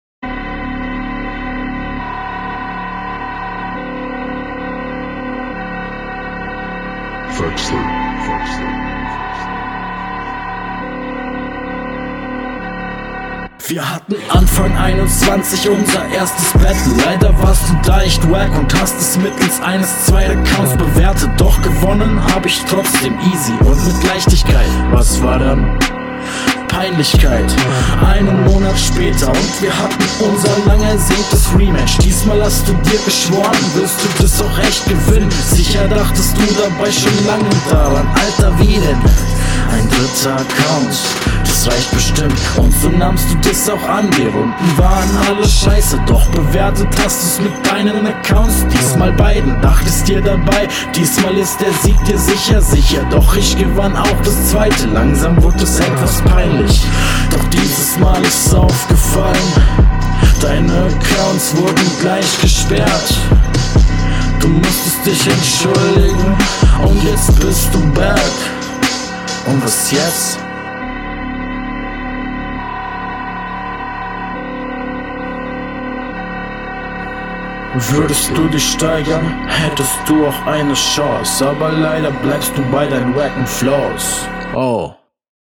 Ui, dieser Vortrag ist ja völlig ohne Elan. Abgesehen davon geht der Flow eigentlich klar.